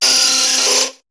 Cri de Nosferapti dans Pokémon HOME.